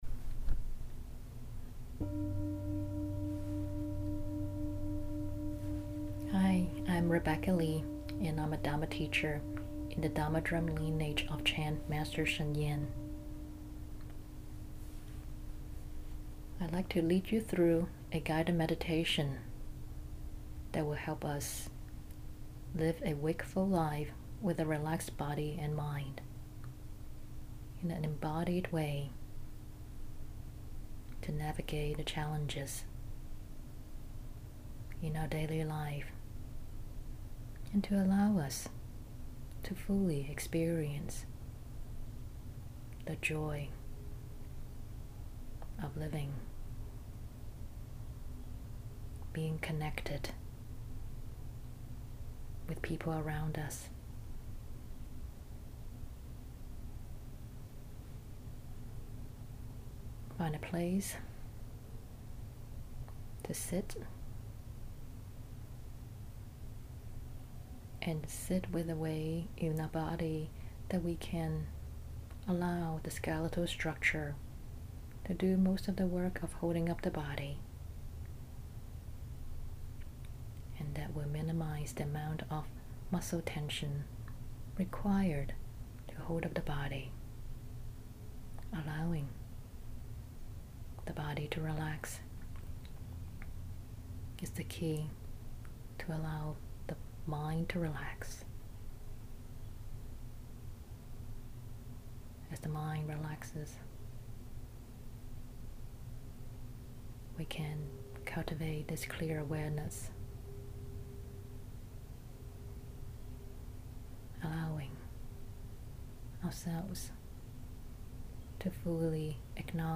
In this guided meditation, I will take you through a whole body relaxation for ten minutes. You can use this for your 10-minute meditation session or continue after the recording ends for a longer period of sitting meditation to practice cultivating clear awareness of your body and mind.